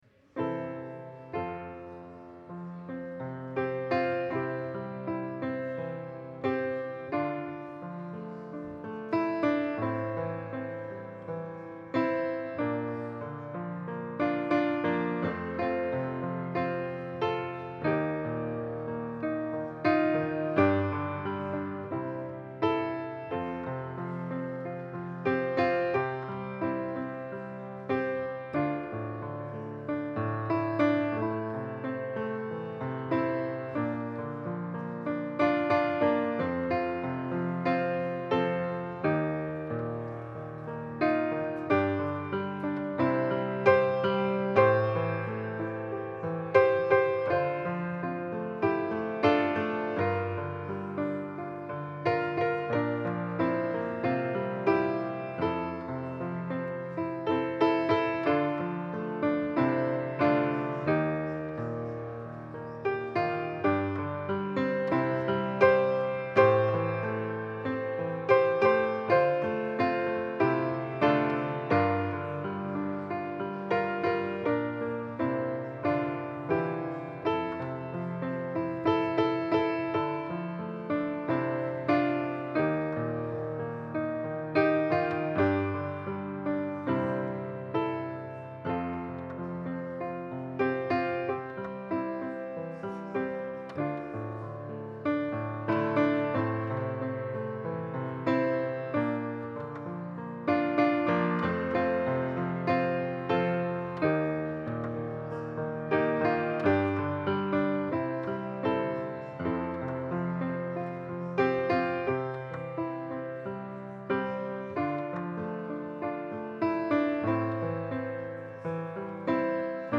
1-15 Service Type: Sunday Service Download Files Bulletin « Imagine Together